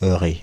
Ääntäminen
Ääntäminen France (Île-de-France): IPA: /œ.ʁe/ Haettu sana löytyi näillä lähdekielillä: ranska Käännöksiä ei löytynyt valitulle kohdekielelle.